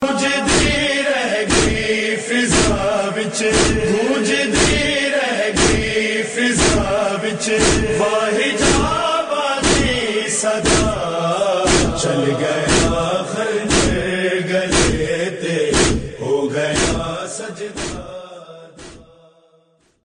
Nohay RingTones